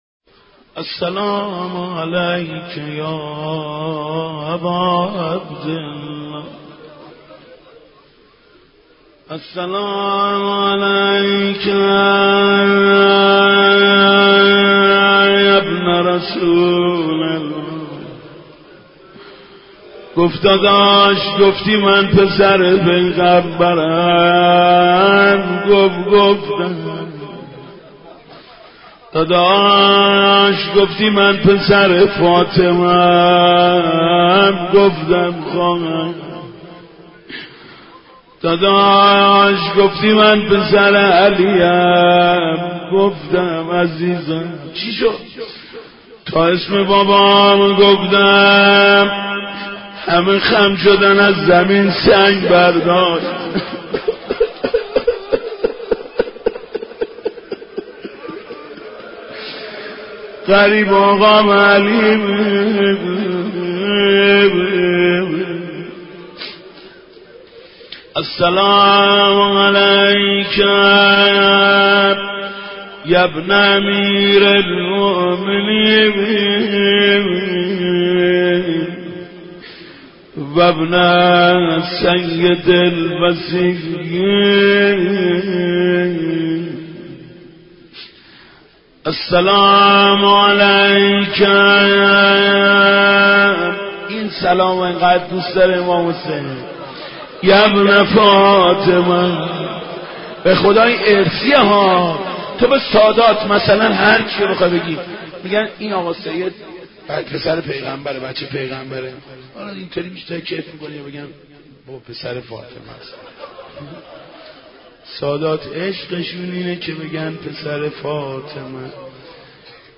دعای راهگشای زیارت عاشورا با صدای مداح اهل بیت استاد محمود کریمی